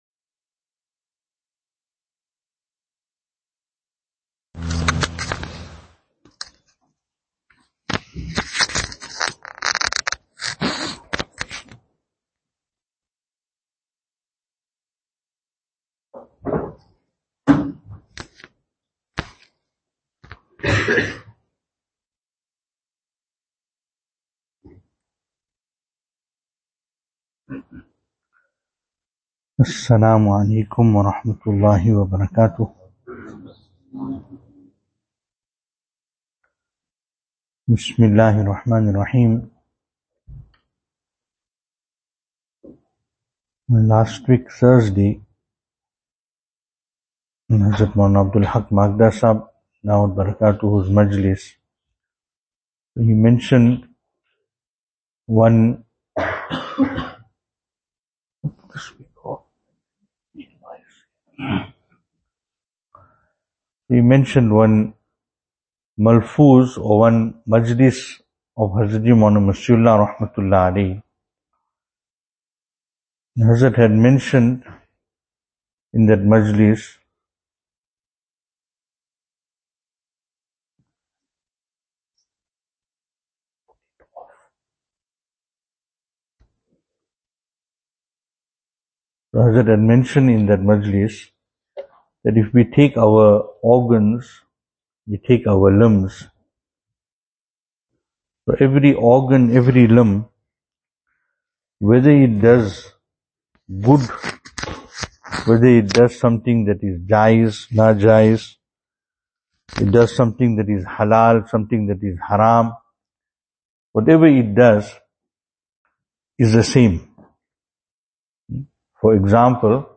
2024-07-22 Keep The Heart Clean Venue: Albert Falls , Madressa Isha'atul Haq Service Type: Zikr